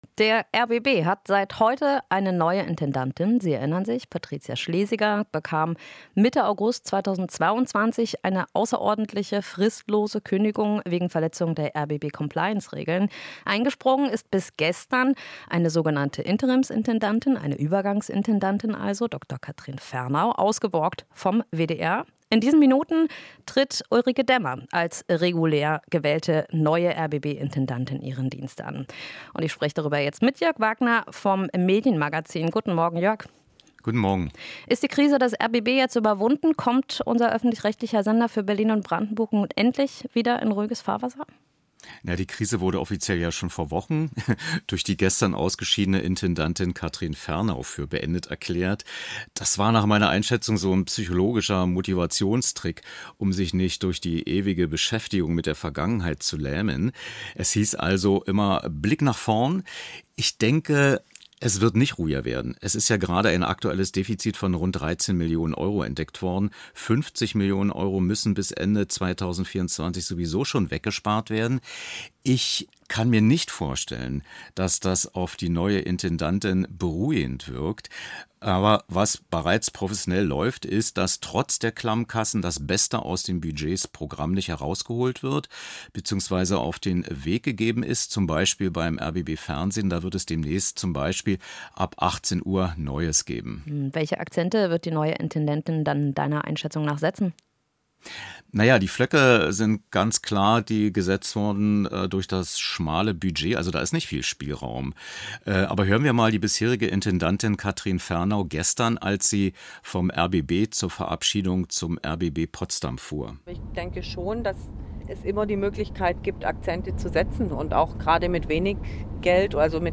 Inforadio Nachrichten, 25.08.2023, 06:40 Uhr - 25.08.2023